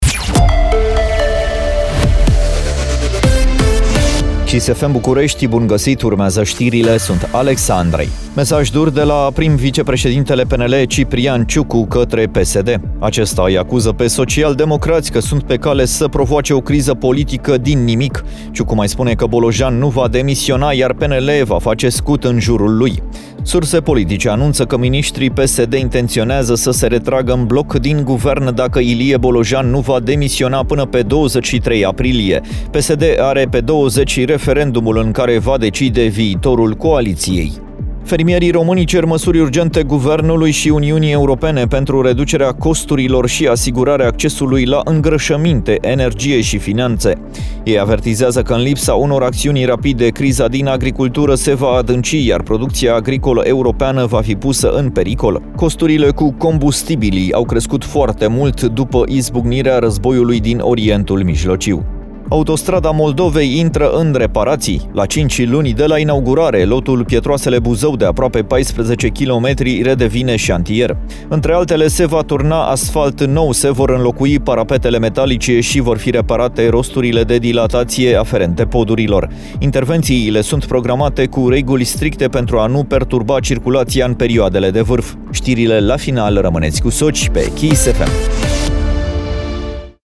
Știrile zilei de la Kiss FM - Știrile zilei de la Kiss FM